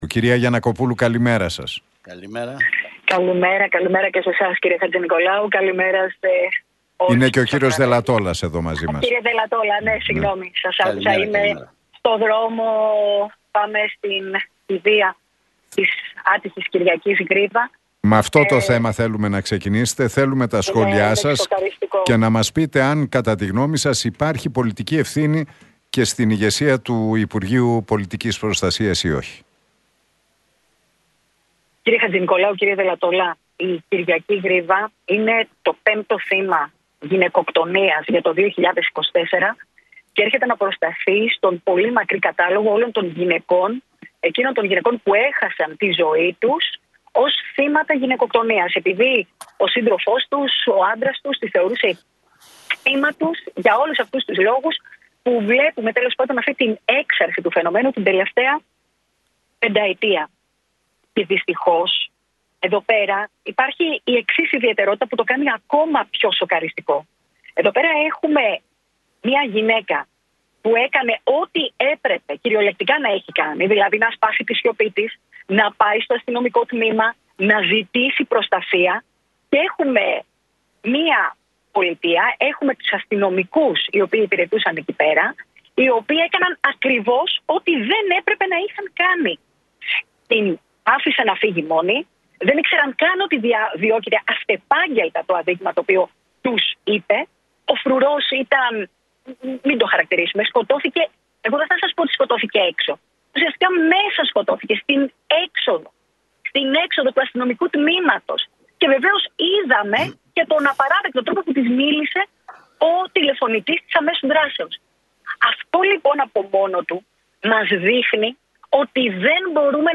Γιαννακοπούλου στον Realfm 97,8: Οι αστυνομικοί έκαναν ό,τι δεν έπρεπε να έχουν κάνει – Ξεκάθαρα υπάρχει πολιτική ευθύνη
«Υπάρχει πολιτική ευθύνη, το λέω ξεκάθαρα» τόνισε στον Realfm 97,8